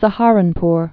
(sə-härən-pr)